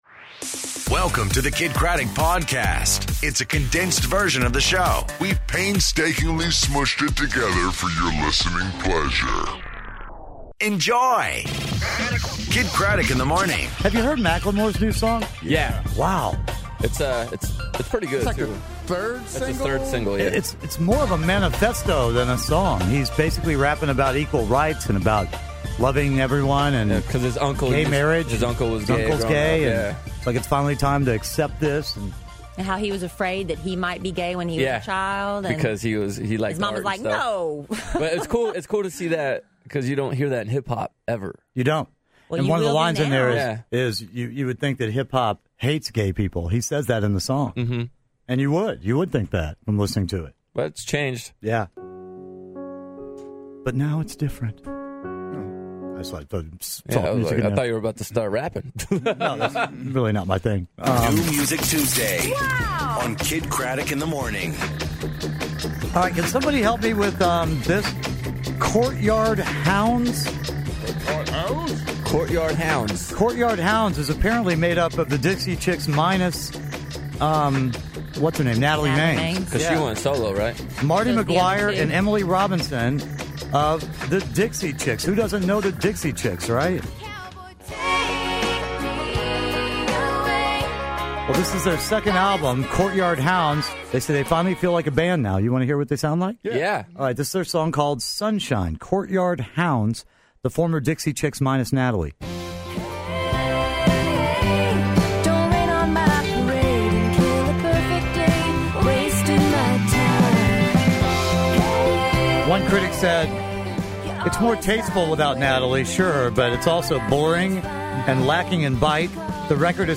New Music Tuesday, Tim Gunn on the phone, and Bachelorette talk